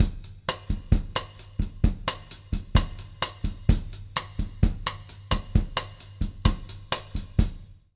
130BOSSA04-L.wav